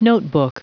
Prononciation du mot : notebook
notebook.wav